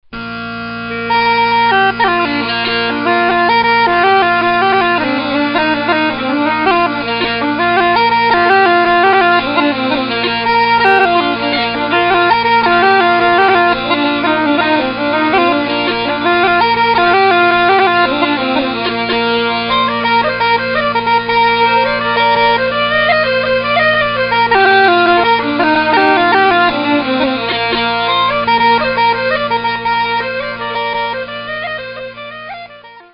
The music on this CD is played in the pitches of B and Bb.
Thus the sound is mellow and smooth.